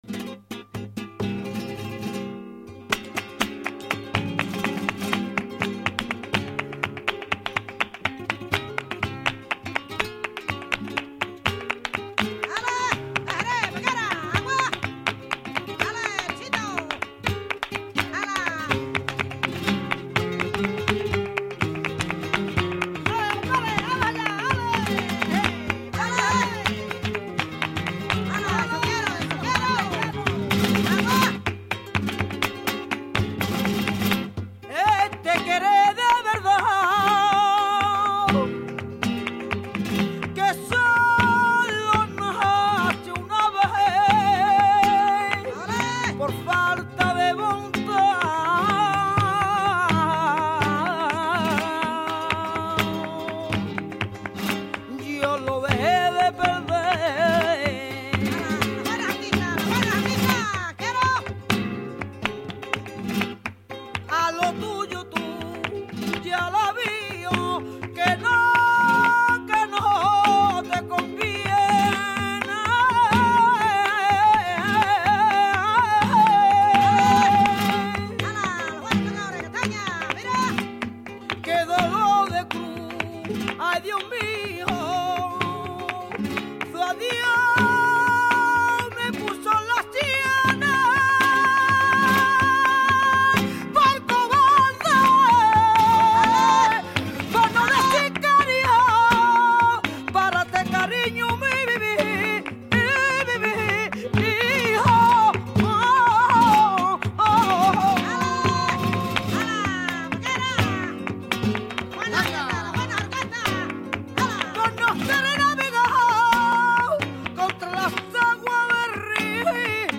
Bulerías